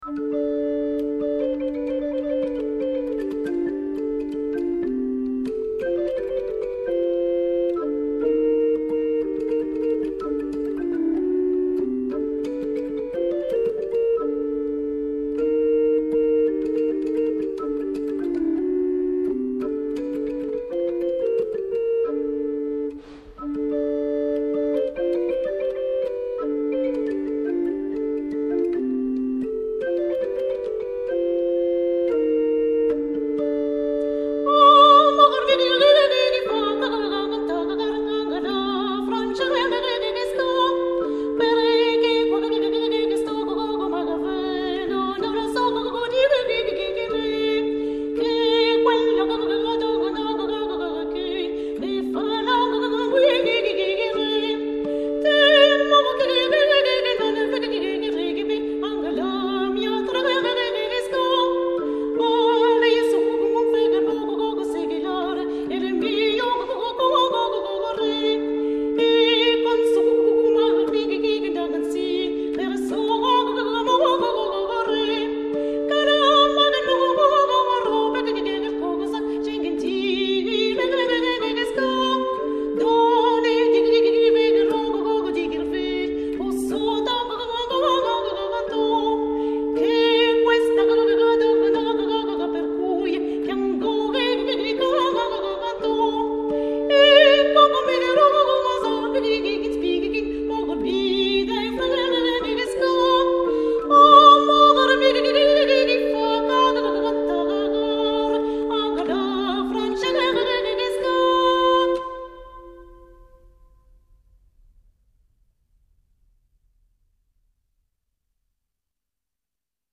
Ballate monodiques de l'Ars Nova Florentine,